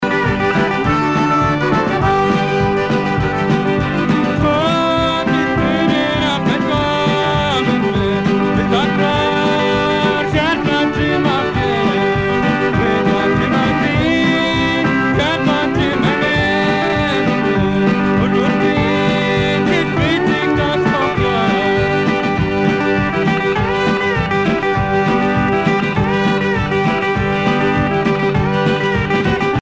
Cajun
Pièce musicale éditée